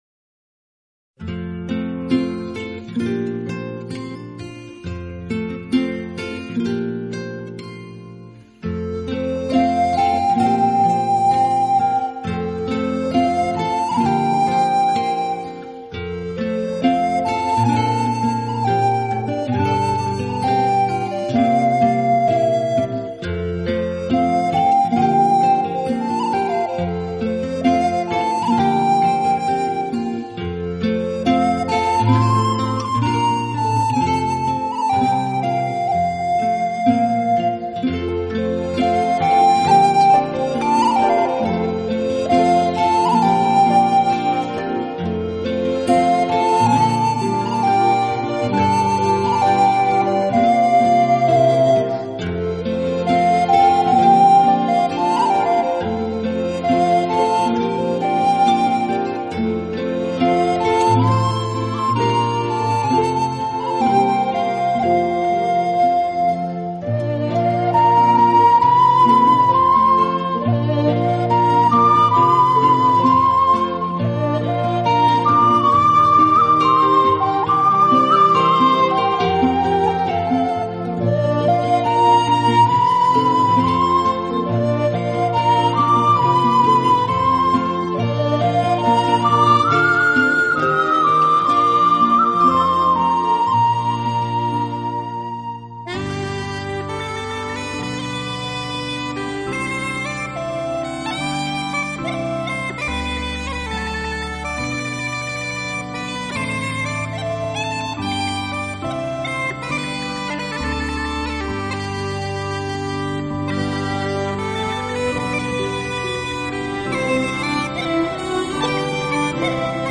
特别使用庞特维拉博物馆的神器--古加利西亚风笛为您演奏悠悠古风。